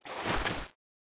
ignitemg_draw1.wav